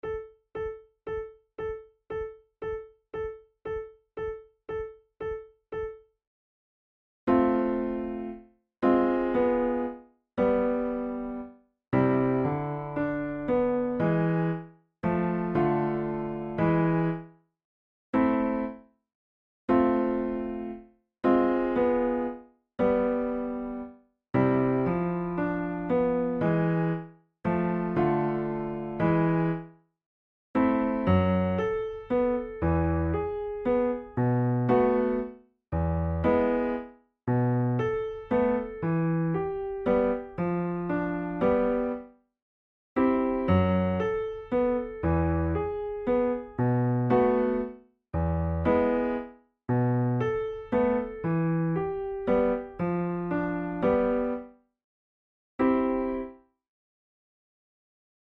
InstrumentationFlute and Piano
KeyA minor
Time signature3/4
Tempo116 BPM
Mazurkas, Romantic
arranged for flute and piano